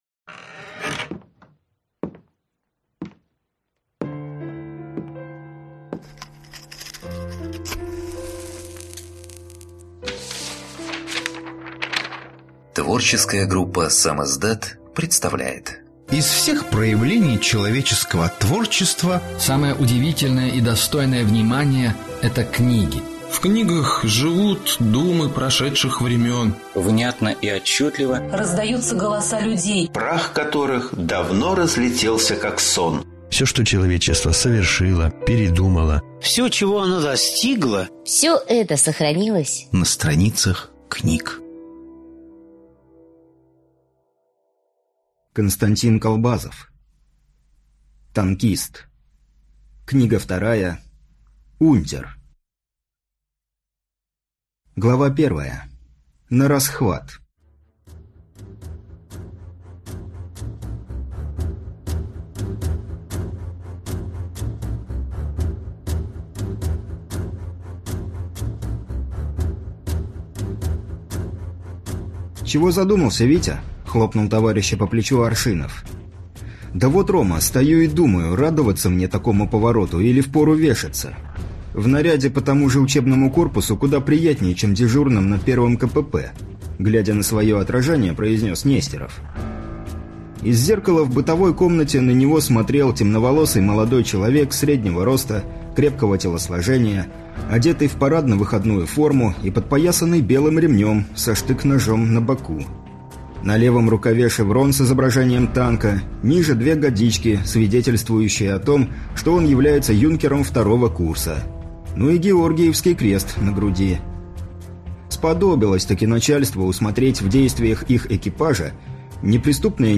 Аудиокнига Танкист. Унтер | Библиотека аудиокниг